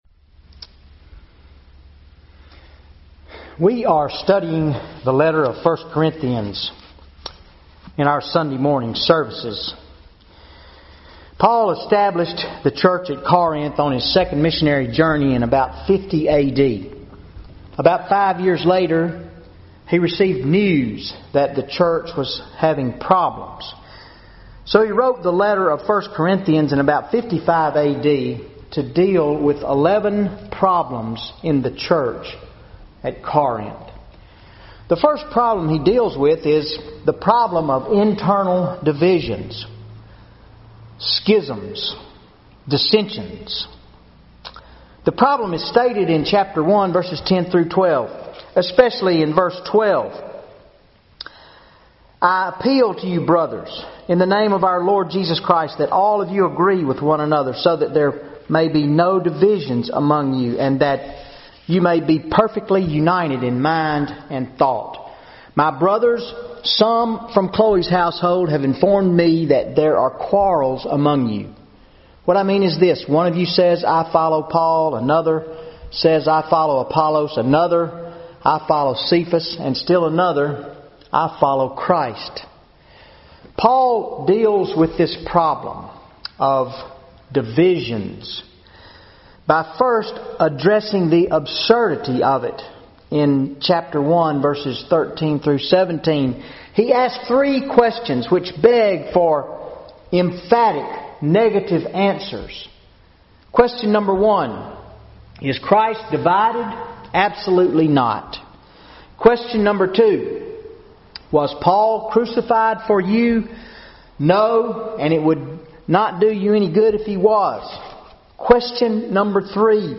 Sunday Sermon October 6, 2013 ICorinthians 2:1-16 How does someone receive the Message of the Gospel so that It Transforms their Life?
Sermon Audio